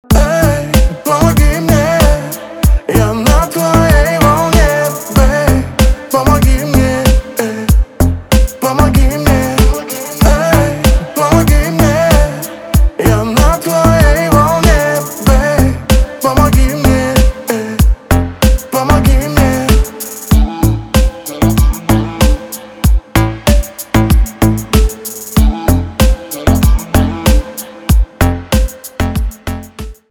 • Качество: 320, Stereo
поп
deep house
чувственные
медленные